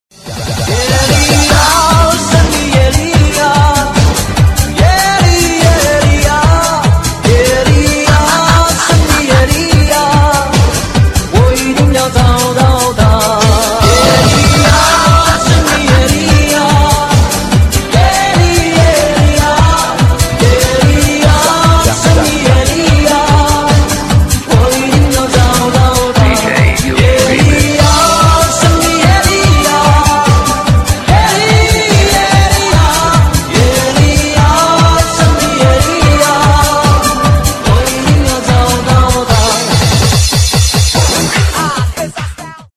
DJ铃声